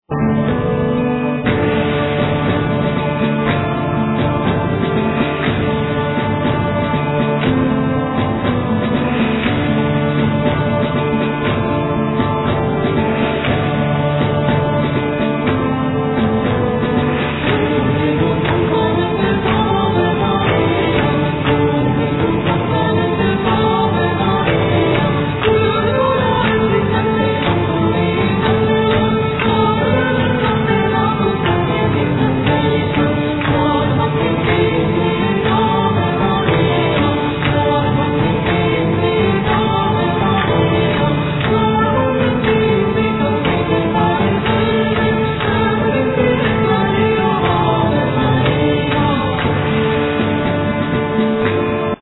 Voice, Percussions, Flute
Guitars
Bass, Drums, Voice
Whispers, Chimes, Bells